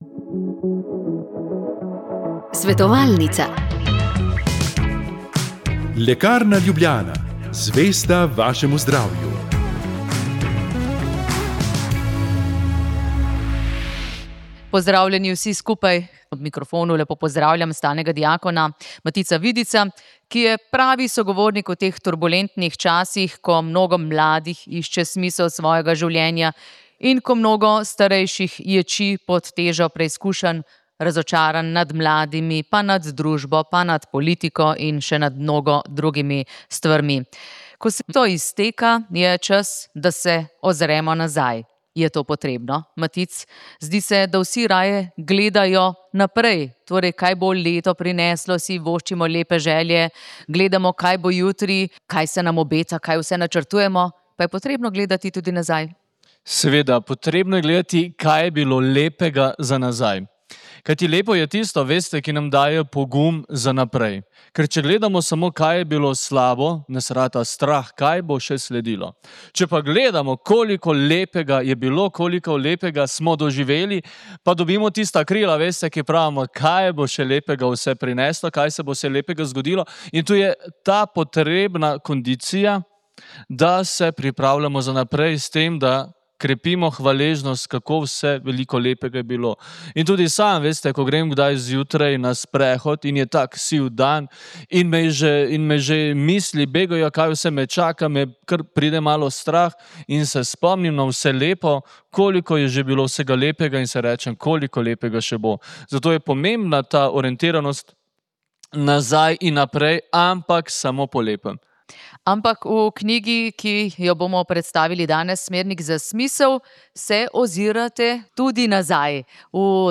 Državni zbor je sprejel spremembe krovnega zakona za Slovence v zamejstvu in po svetu. Kako jih je predstavila državna sekretarka Vesna Humar, ste lahko slišali na začetku.